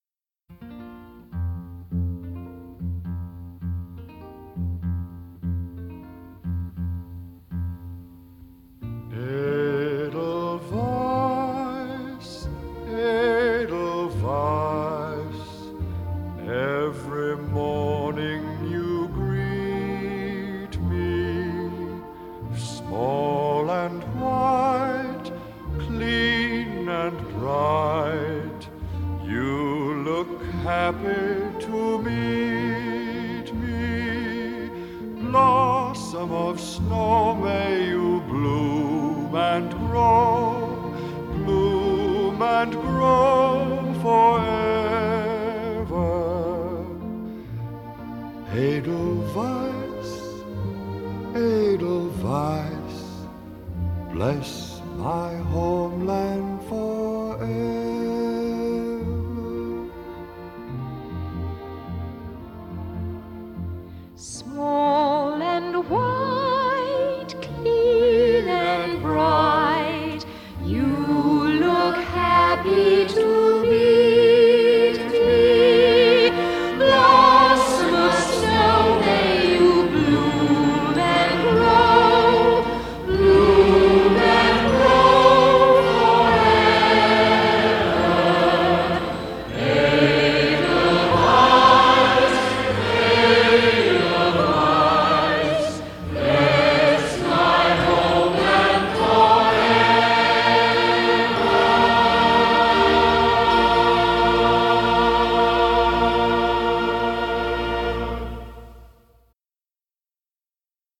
歌曲曲调优美，朗朗上口，也许您可以惬意地哼上几句！